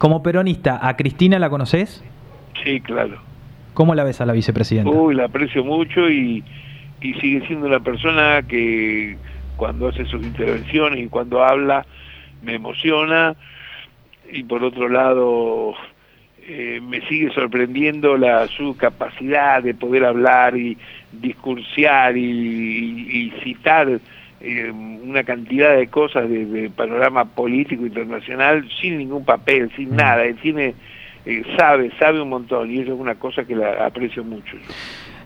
Entrevistas
El músico y cantante en diálogo con «Todo es Posible» por La 105 y La 100 en Casares se refirió a la relación con el Presidente Alberto Fernández y sobre la decisión de Alberto de dejarse el bigote por él: